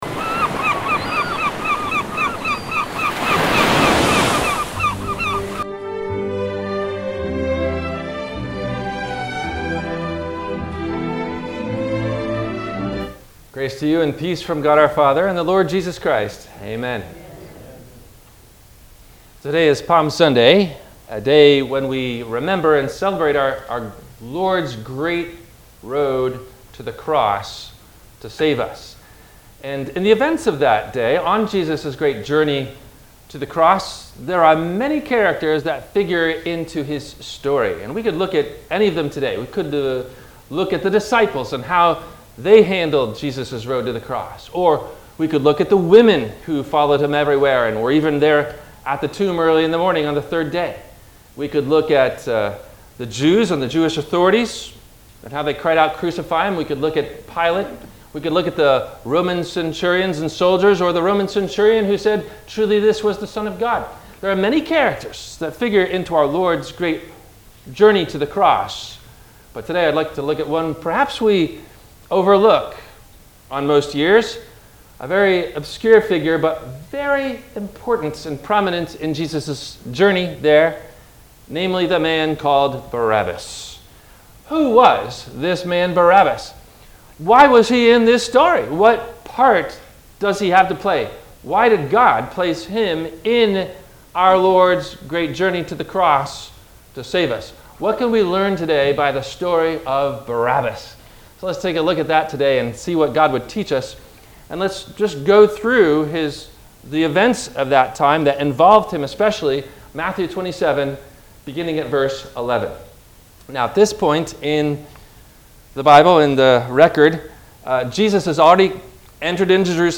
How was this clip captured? Including closing Plug only.